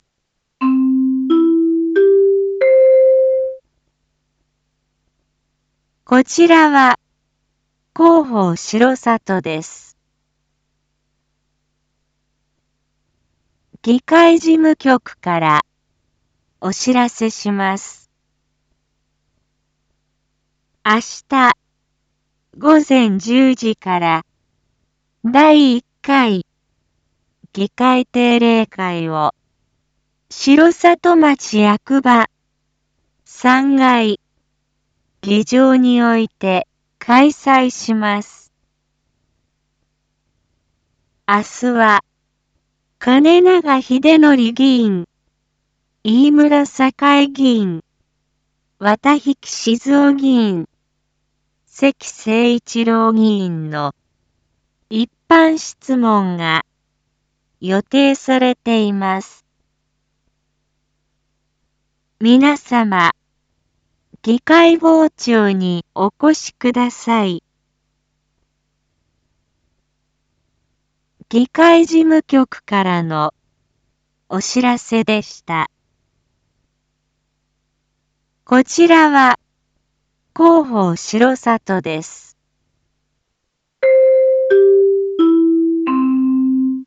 Back Home 一般放送情報 音声放送 再生 一般放送情報 登録日時：2024-03-11 19:01:28 タイトル：第１回議会定例会③ インフォメーション：こちらは広報しろさとです。